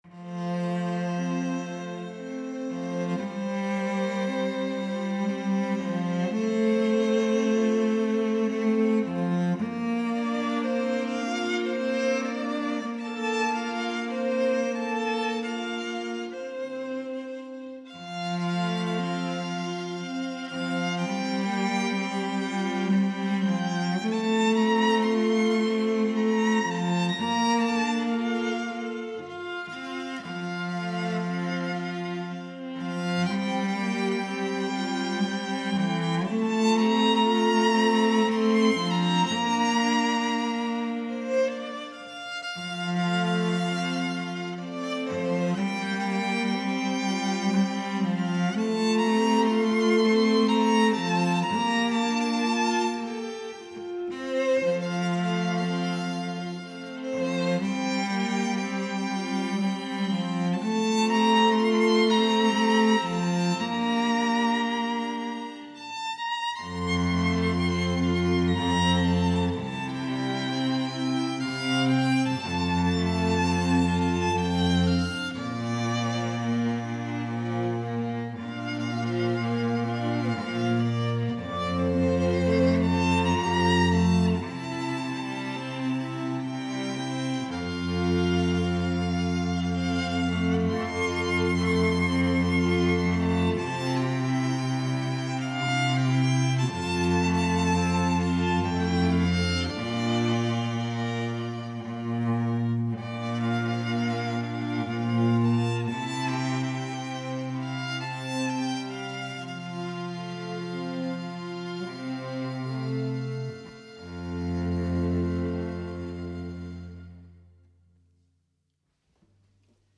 THE QUARTET
Scottish